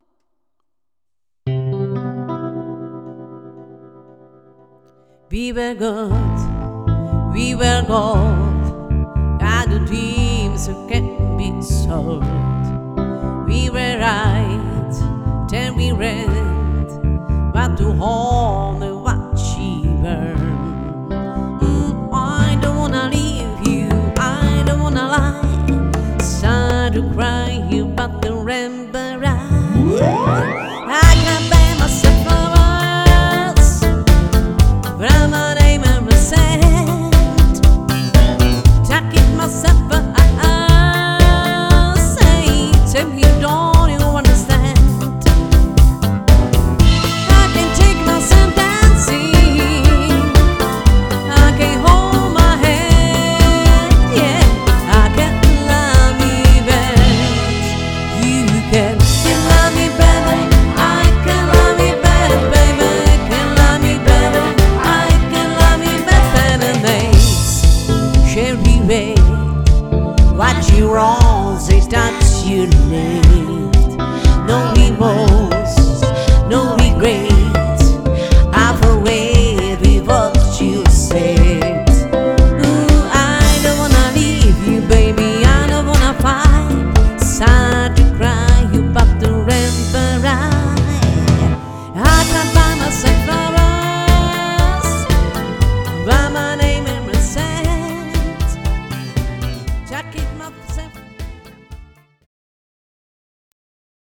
UKÁZKY PRO DUO